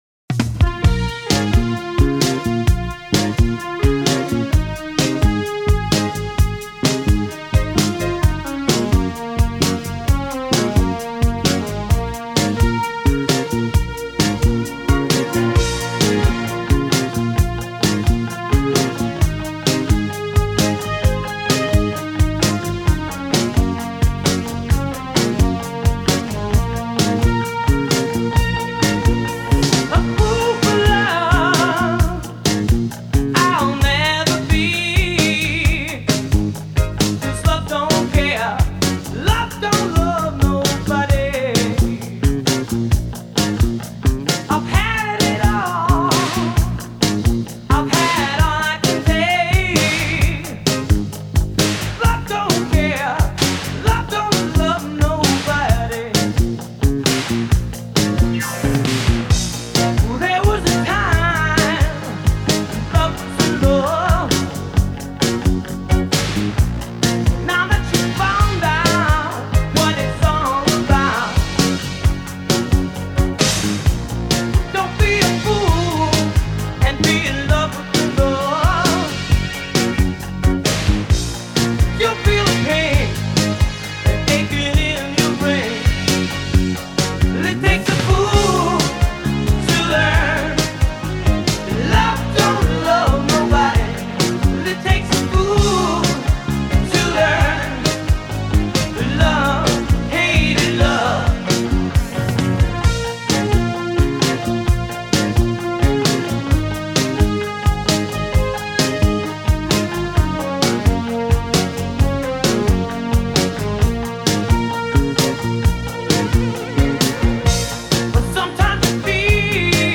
реггей